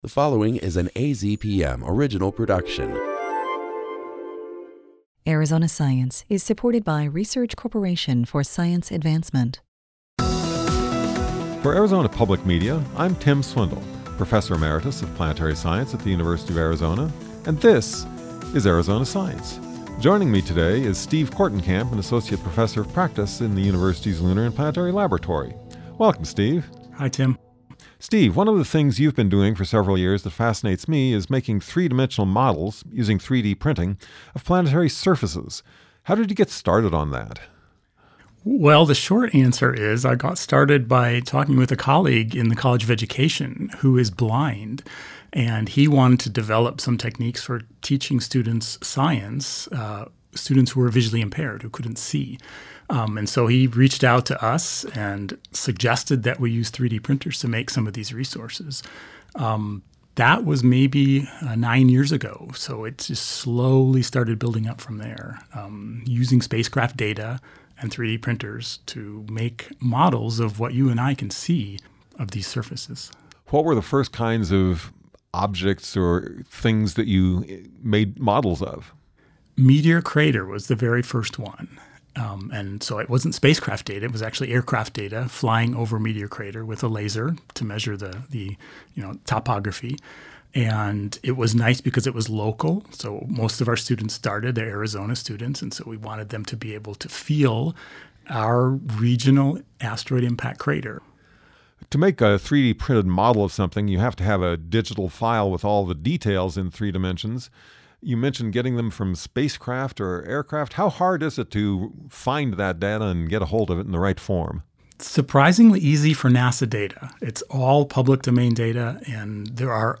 Presenter(s)